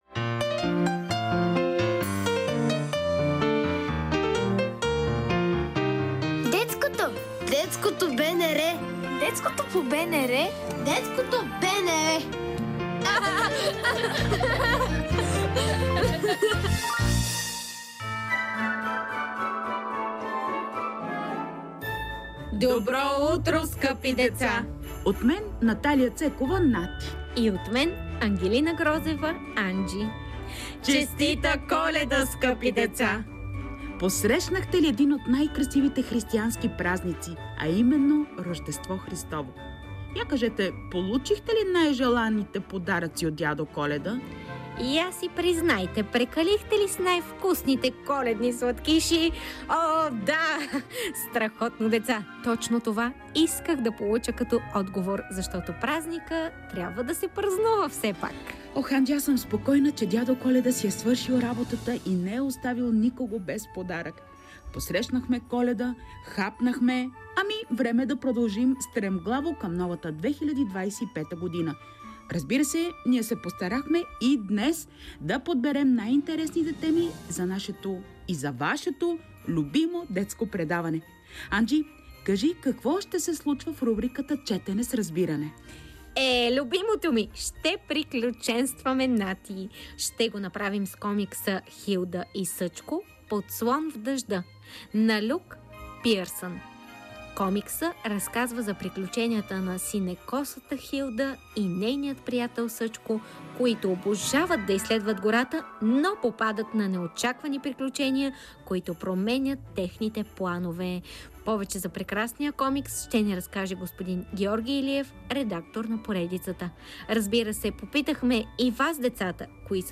Разбира се попитахме и вас, децата, какво знаете за северното сияние и дали обичате приключенията.